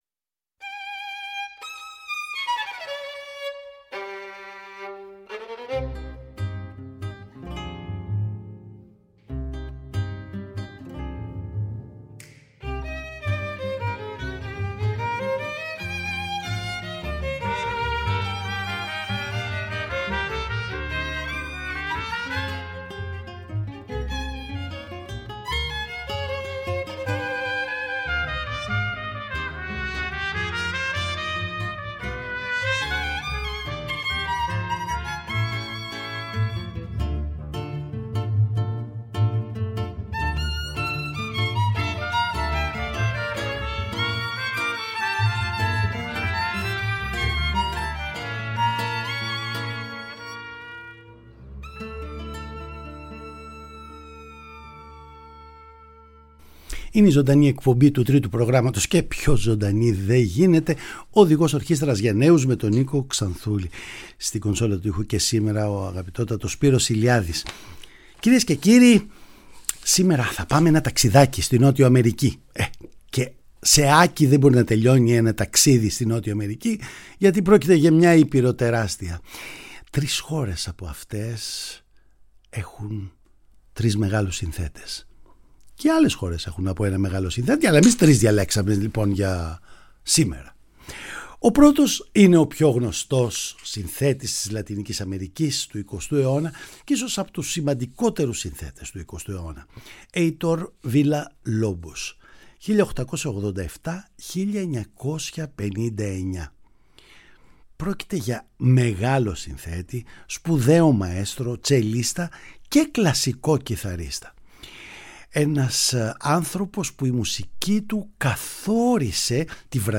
Τρεις Νοτιοαμερικανοί συνθέτες: ένας Βραζιλιάνος, ένας Βενεζουελάνος και ένας Αργεντίνος συνομιλούν στην όμορφη εξωτική γλώσσα της δικής τους μουσικής μπολιασμένης όμως, με τη σιγουριά της Ευρωπαϊκής κλασικής παράδοσης.